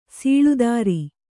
♪ sīḷu dāri